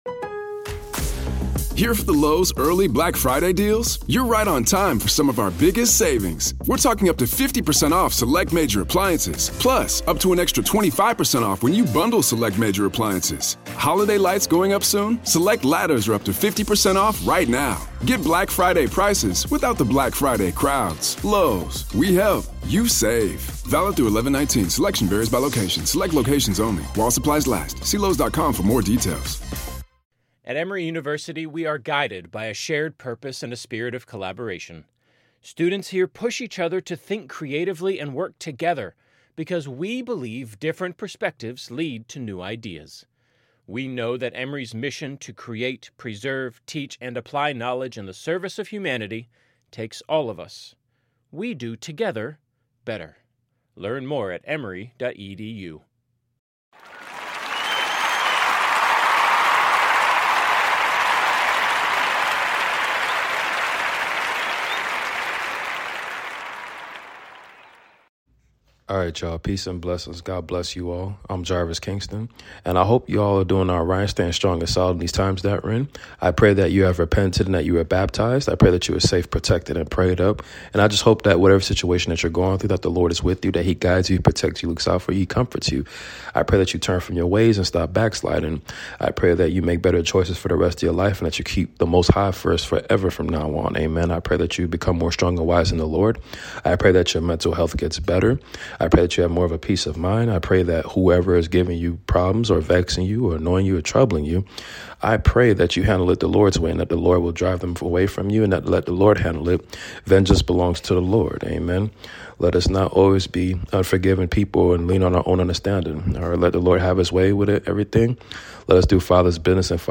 Book of Amos reading completion 1-9. The Lord will gather and restore us!